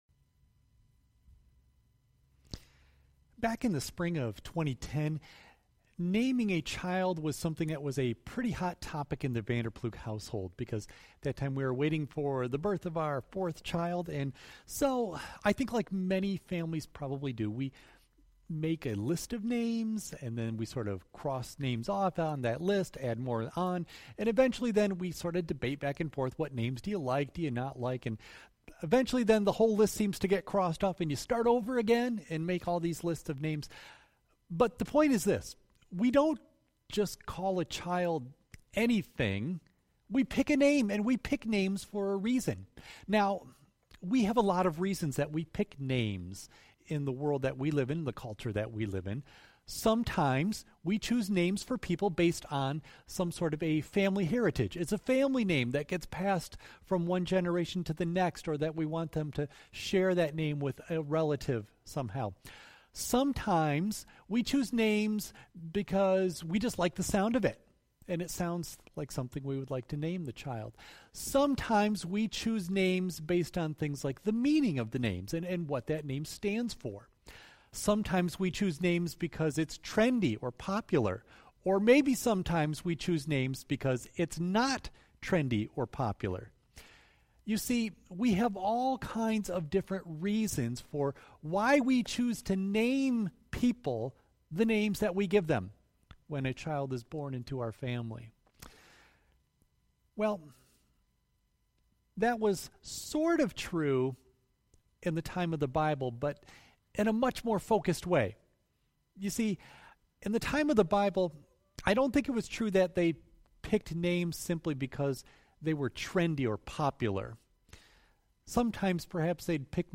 Worship Service June 28 Audio only of message